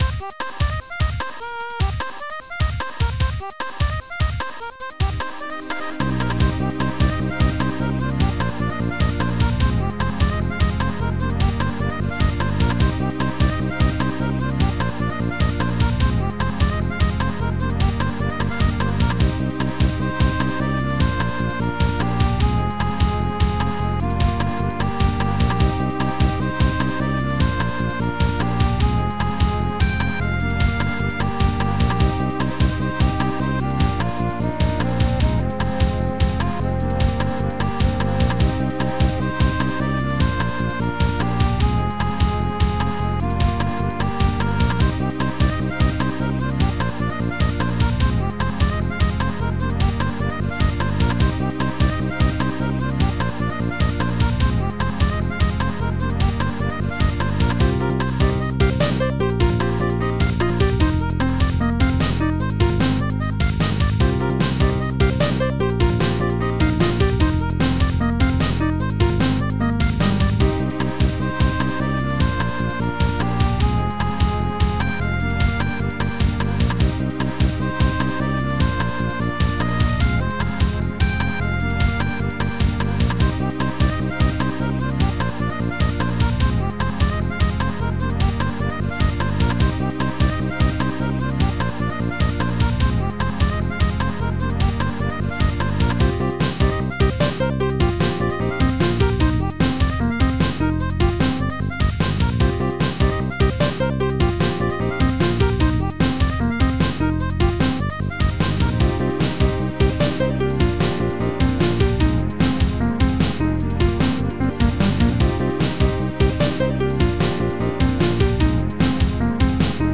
Protracker Module
2 channels